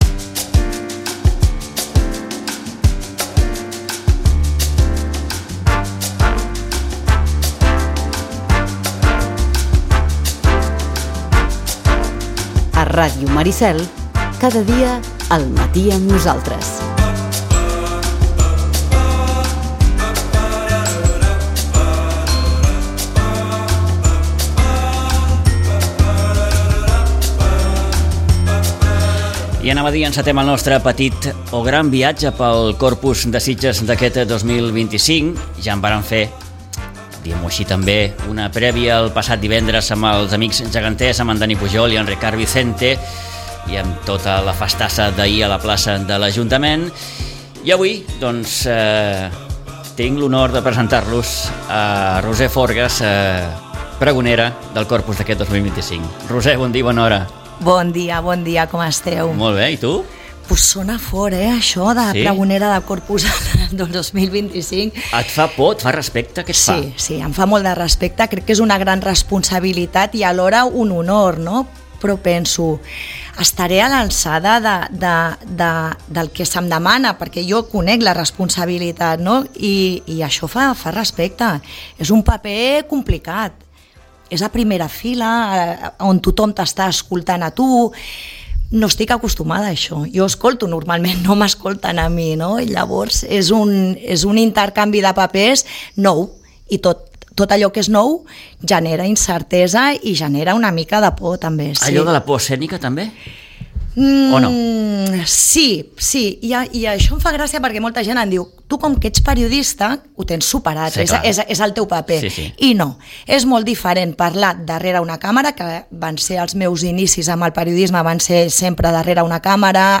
Ràdio Maricel. Emissora municipal de Sitges. 107.8FM. Escolta Sitges.